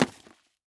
book_fall.L.wav